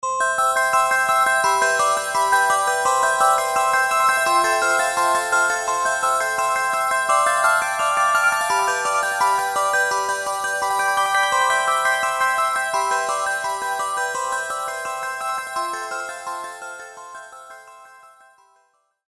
Рингтоны без слов , громкие рингтоны
звонкие